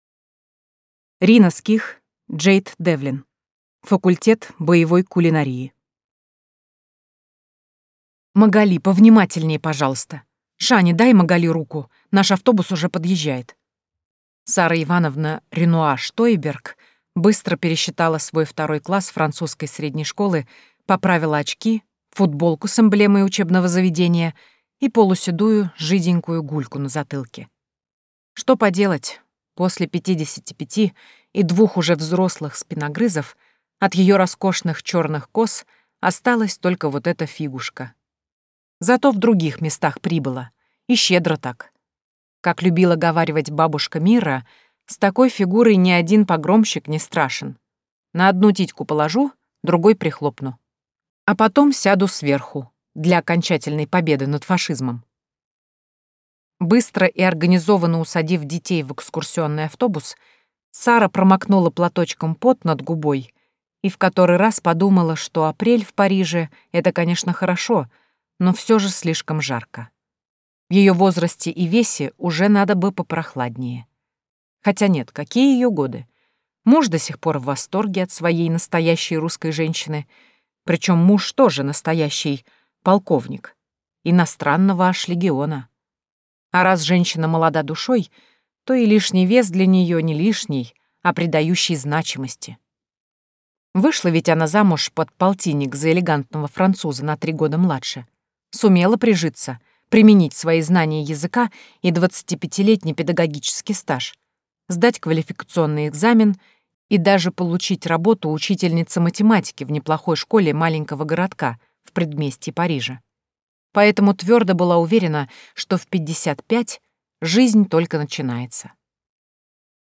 Аудиокнига Факультет боевой кулинарии | Библиотека аудиокниг